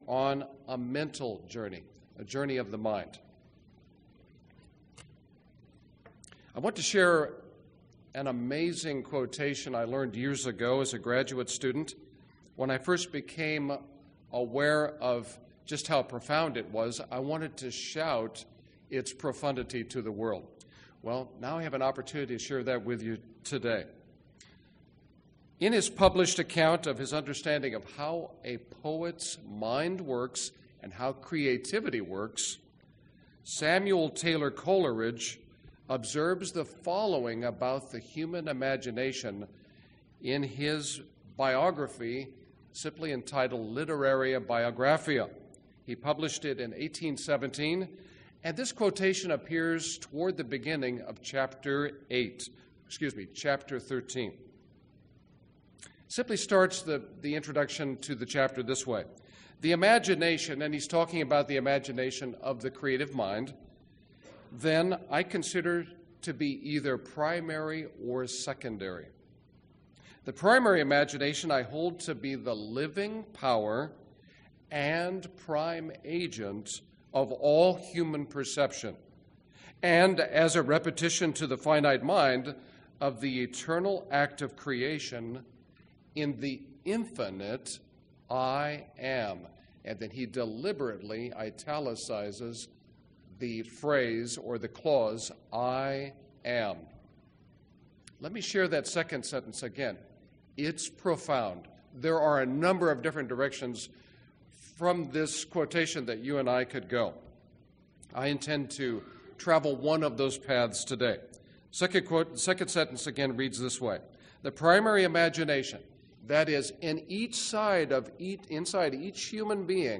Given in Dallas, TX
UCG Sermon Studying the bible?